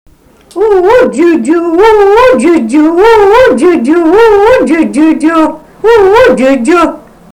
smulkieji žanrai